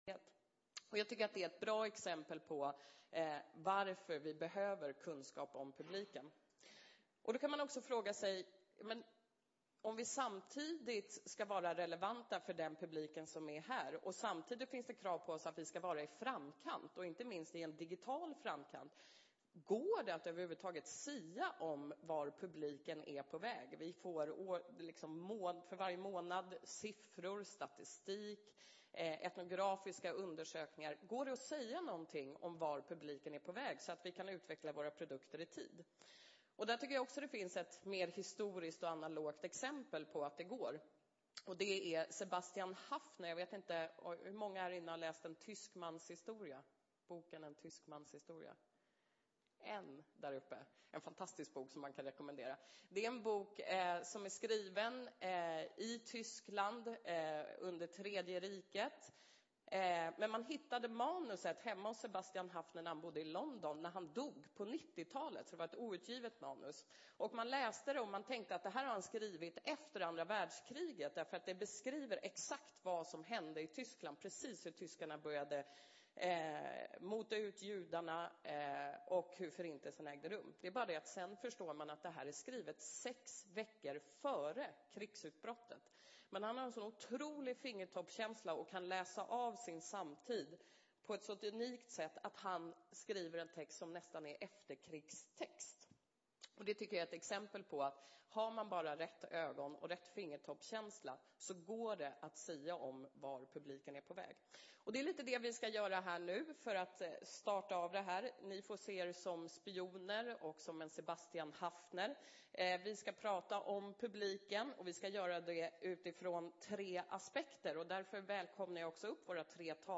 Publiken: Nya aktörer och nya mediavanor Plats: Kongresshall B Datum: 2010-10-26 Tid: 11:00-12:30 Många svenskar har använt Internet i snart tio år och de flesta är dagliga användare.